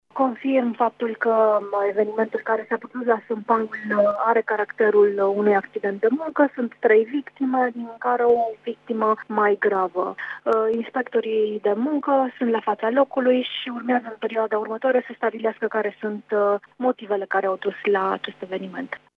Şefa Inspectoratului Teritorial de Muncă Mureş, Eva Man: